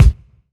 • Kick Drum Sound C Key 156.wav
Royality free bass drum single hit tuned to the C note. Loudest frequency: 247Hz
kick-drum-sound-c-key-156-Xdu.wav